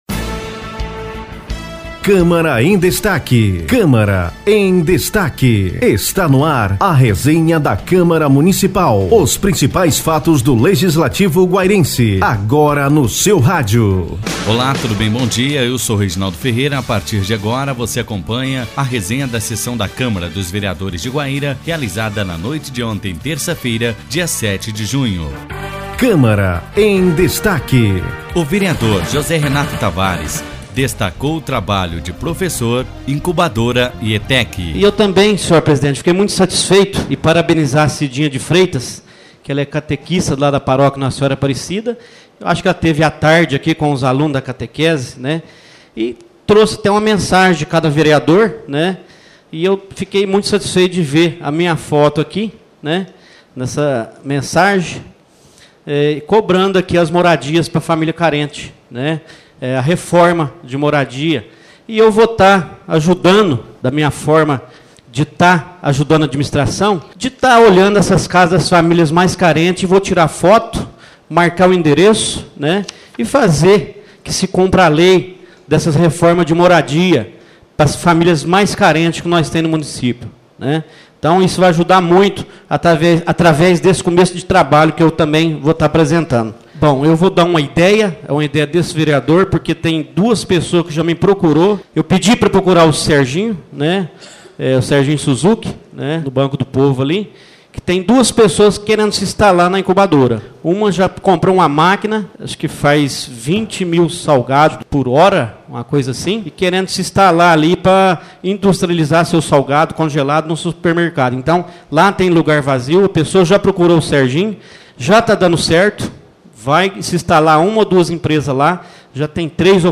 Ouça a resenha da Sessão da Câmara Municipal de 07 de junho